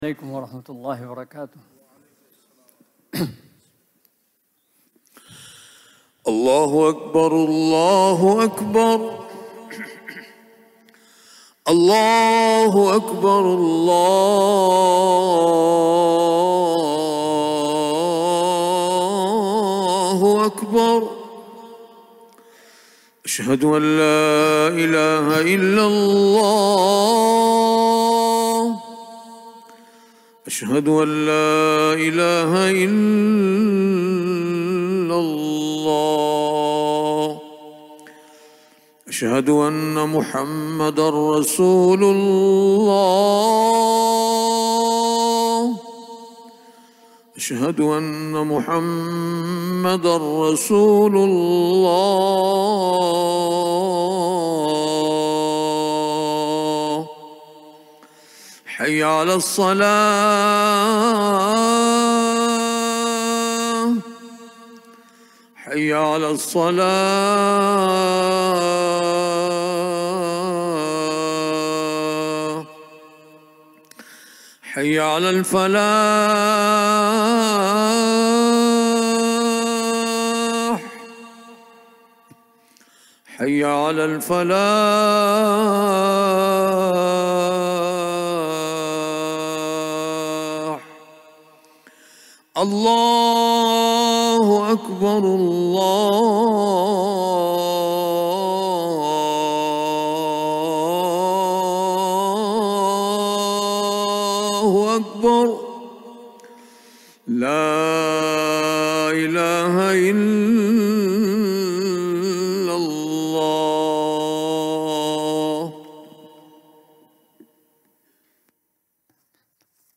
Friday Khutbah - "Dealing with Desires"